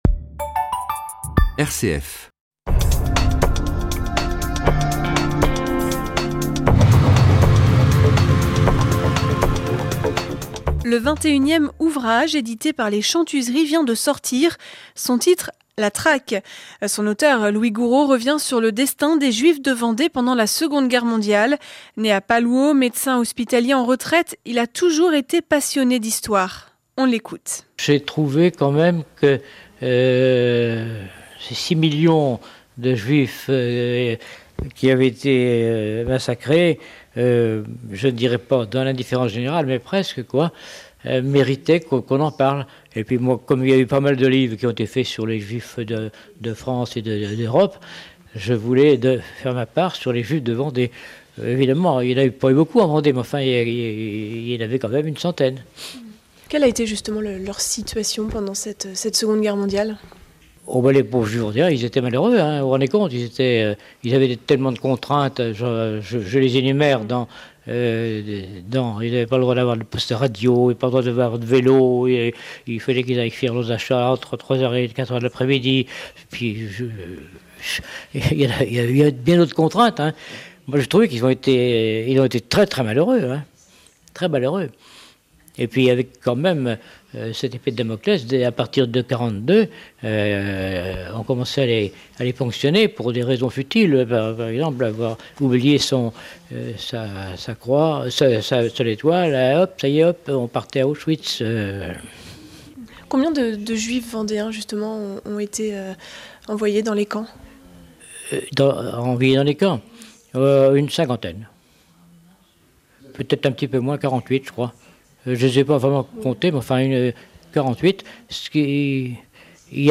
Le 18 mars, Fidélité Nantes organisait un débat en vue des prochaines élections départementales des 22 et 29 mars.
Débat / table ronde éléction départementales